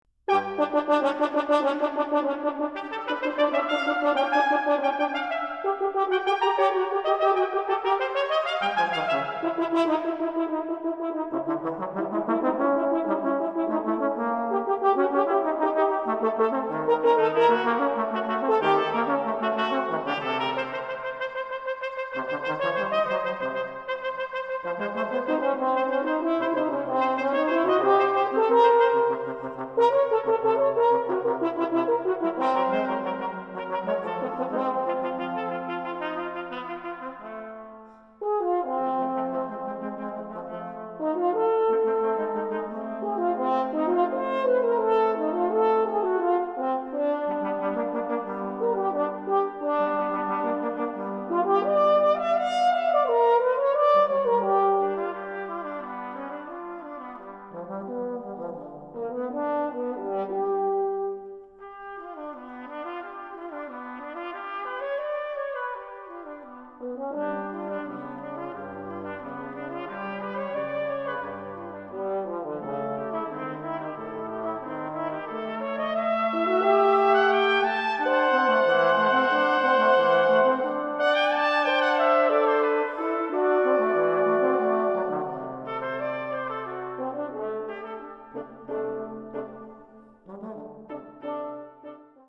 trumpet
french horn
trombone